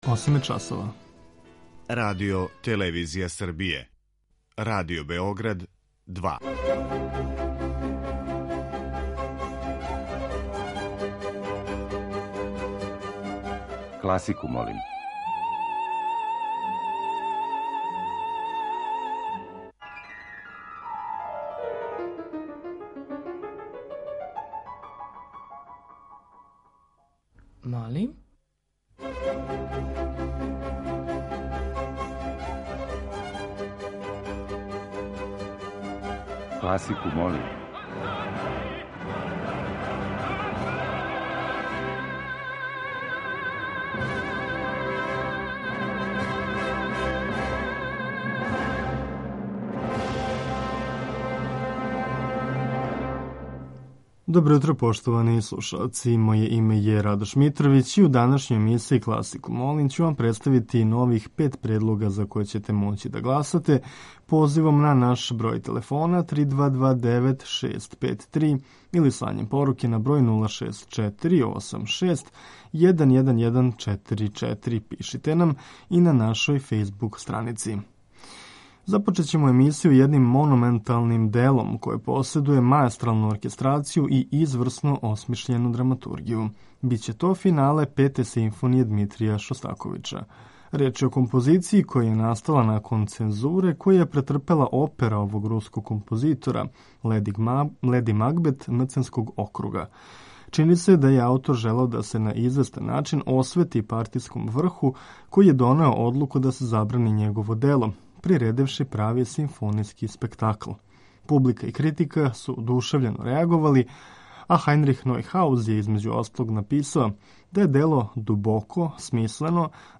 Ове недеље ћемо као окосницу емисије имати жанр барокног концерта.
Чућемо неколико познатих, али и мање познатих концерата за различите инструменте који су настајали у славној епохи барока.
Уживо вођена емисија, окренута широком кругу љубитеља музике, разноврсног је садржаја, који се огледа у подједнакој заступљености свих музичких стилова, епоха и жанрова.
klasika.mp3